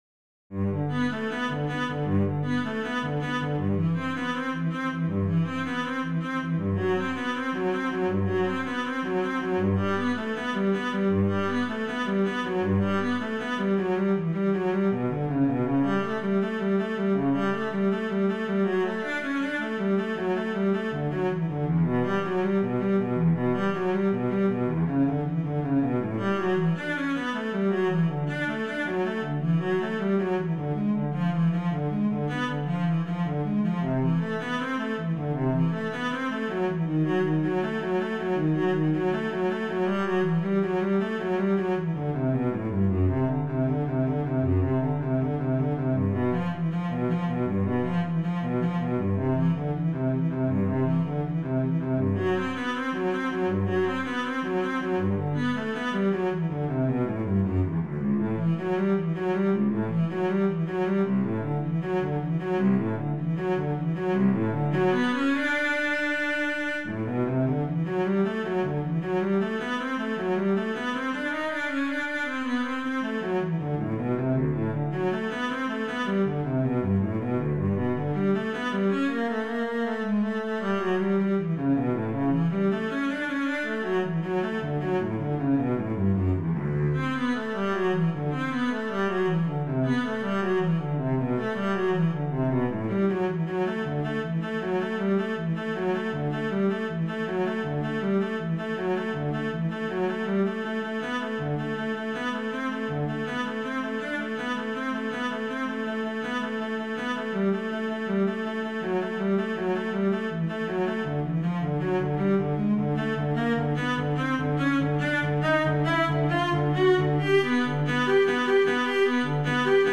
Solo Cello
it is the first movement of the first Suite in G major